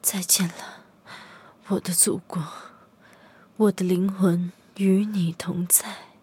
LT-35被击毁语音.OGG